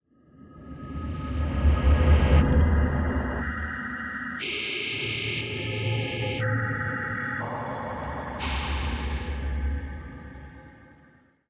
Minecraft Version Minecraft Version latest Latest Release | Latest Snapshot latest / assets / minecraft / sounds / ambient / cave / cave14.ogg Compare With Compare With Latest Release | Latest Snapshot
cave14.ogg